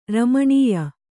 ♪ ramaṇīya